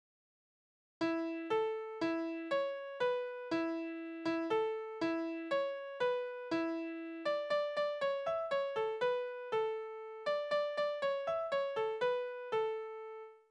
Balladen: Moritat
Tonart: A-Dur
Taktart: 3/4
Tonumfang: Oktave
Besetzung: vokal
Anmerkung: Vortragsbezeichnung: Innig, Langsam